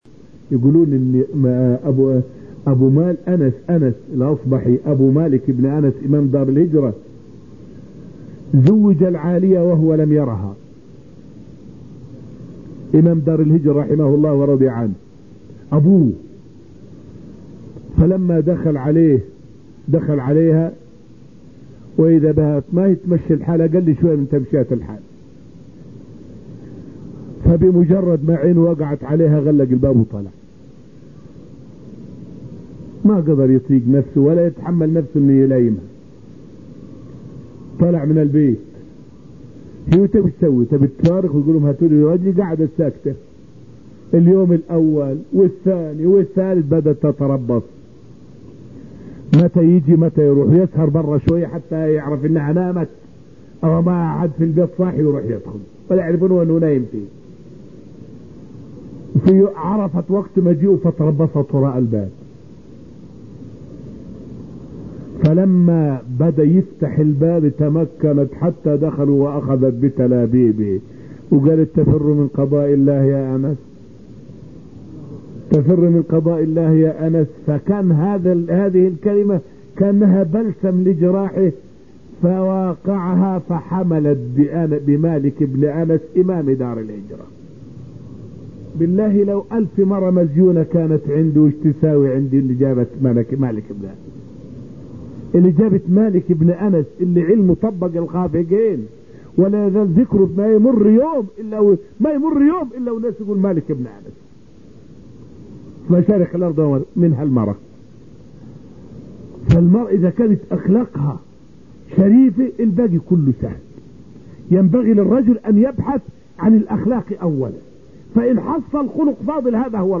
فائدة من الدرس الثاني عشر من دروس تفسير سورة الرحمن والتي ألقيت في المسجد النبوي الشريف حول قصة لطيفة حصلت بين والد الإمام مالك وأمه أو زواجهما.